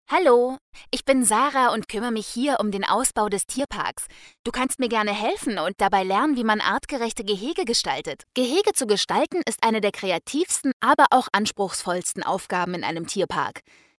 Seit dem bin ich professionelle Sprecherin für Kino/TV und Hörfunk Spots, XBox/PlayStation und PC Spiele, Synchron, Voice Over, Dokumentationen, Hörbücher und Hörspiele, Imagefilme/Industriefilme, E-Learning, Telefonansagen, Navigationssysteme, u.v.m. Meine Stimmlage ist Sopran und mein Stimmalter fällt in die Kategorie „Jugendliche bis junge Erwachsene“.
Meine Stimmfarbe lässt sich mit den Worten „jung, dynamisch, frisch, frech, facettenreich, jugendlich, mittelkräftig, sanft, seriös, sinnlich, warm und weich“ beschreiben.
Sprecherin Deutsch & Türkisch akzentfrei.
Sprechprobe: eLearning (Muttersprache):